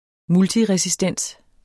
Udtale [ ˈmulti- ]